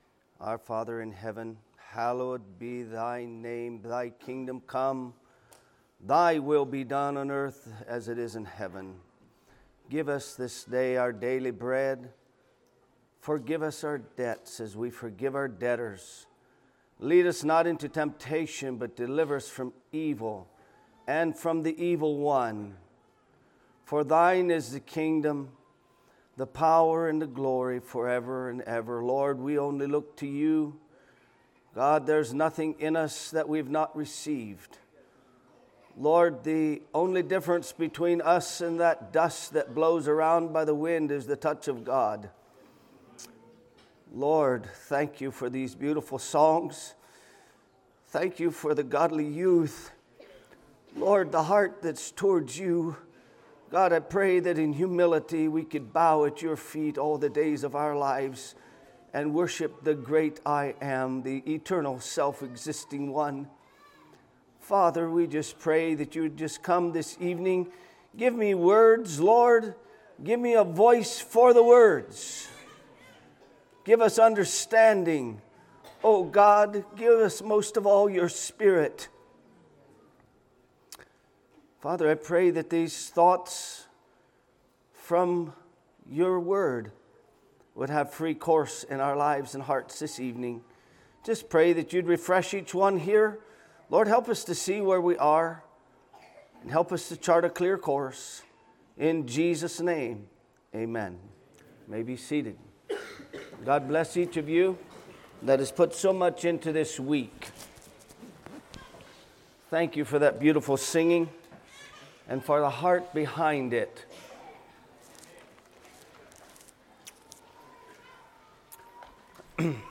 Sermons of 2019 - Blessed Hope Christian Fellowship